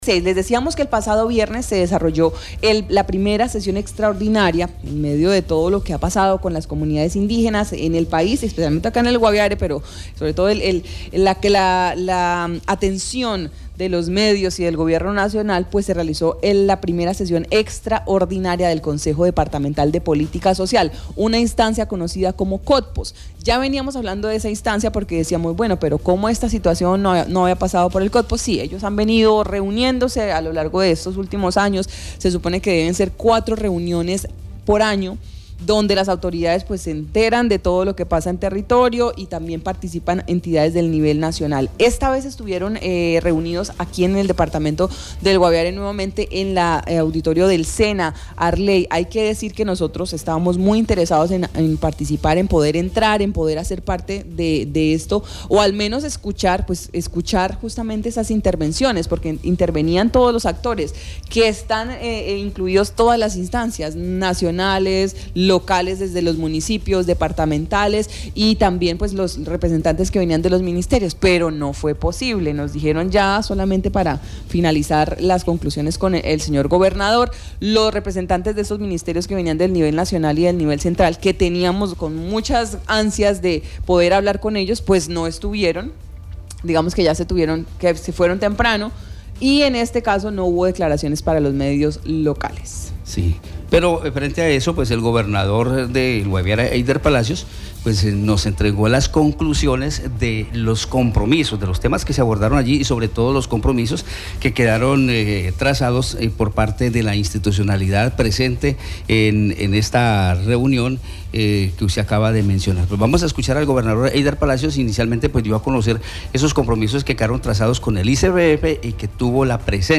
Gobernador del Guaviare, Heydeer Palacio, en Marandua Noticias entregó balance sobre los compromisos por la institucionalidad en el desarrollo de la de sesión extraordinaria del Consejo Departamental de Política Social CODPOS.